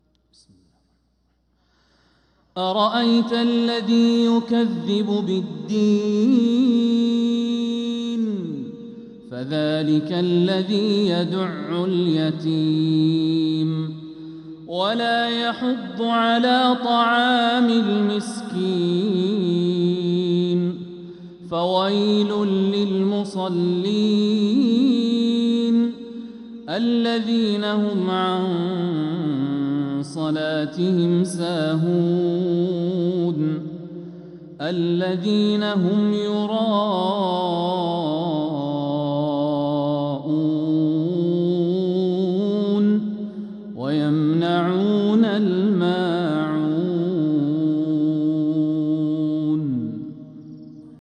سورة الماعون | فروض ربيع الأخر 1446هـ
من الحرم المكي